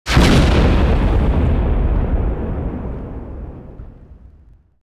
BombExplode.wav